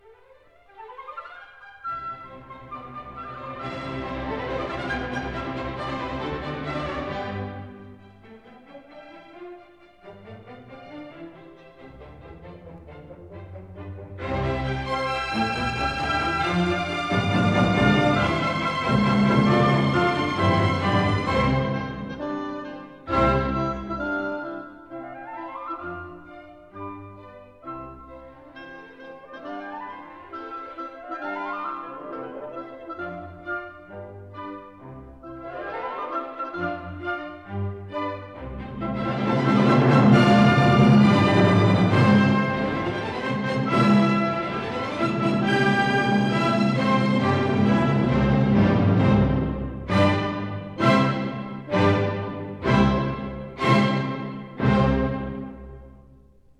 in C major, Op. 21
in the Kingsway Hall, London